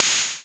• Tamed Urban Open Hat C# Key 1.wav
Royality free open hat tuned to the C# note. Loudest frequency: 4793Hz
tamed-urban-open-hat-c-sharp-key-1-AOm.wav